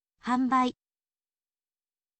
hanbai